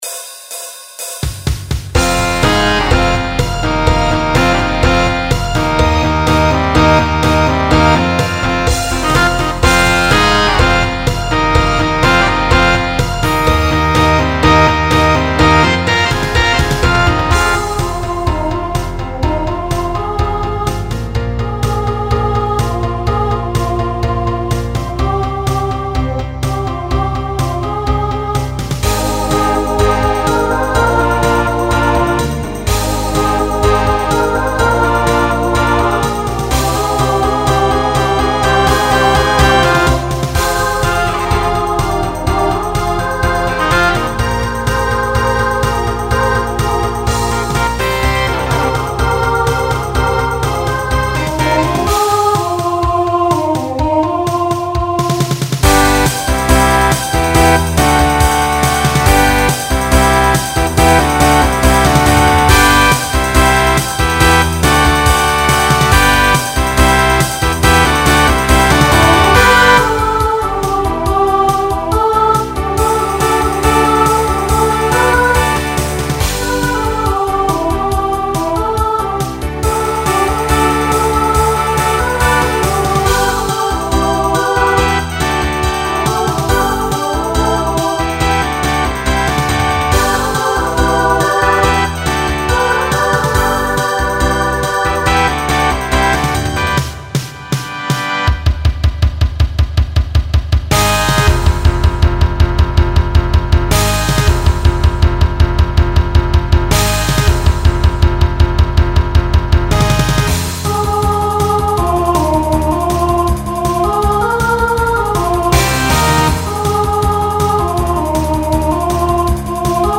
Genre Rock
Voicing SSA